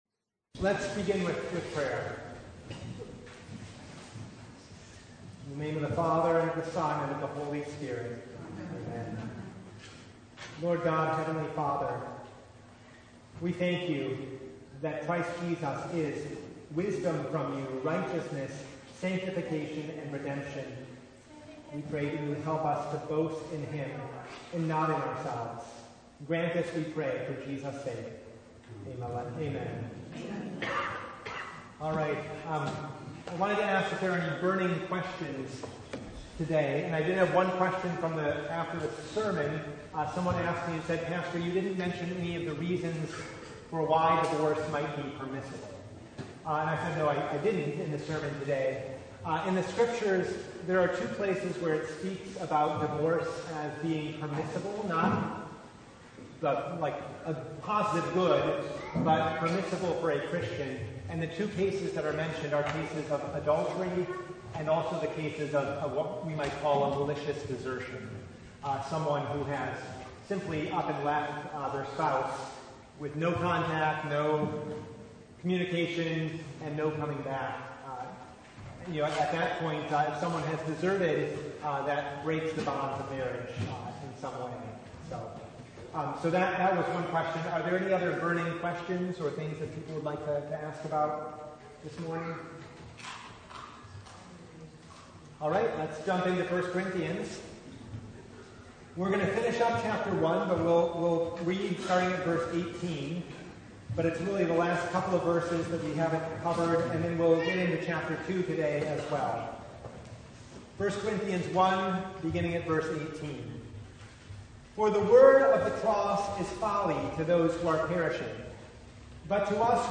1 Corinthians 1:30-2:9 Service Type: Bible Hour Topics: Bible Study « Jesus Gives the Good Life The Third Sunday in Angels’ Tide